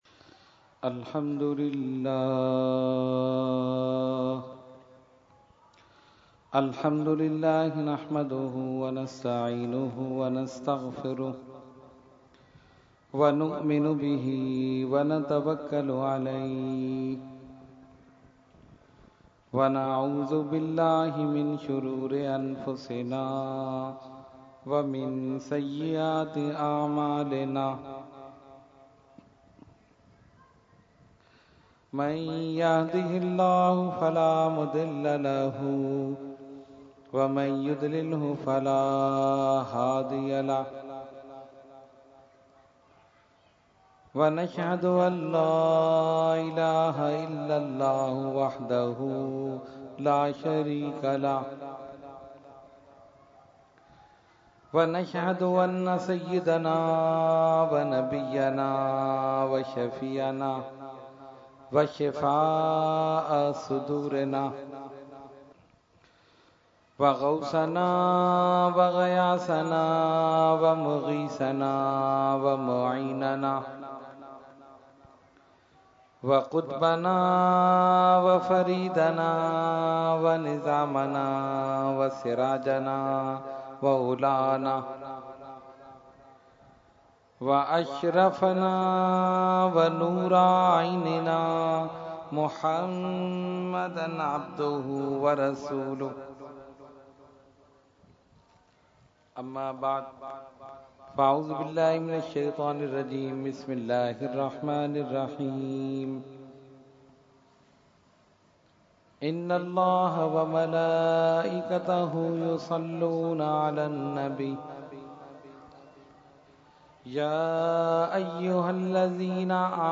Category : Speech | Language : UrduEvent : Muharram 2016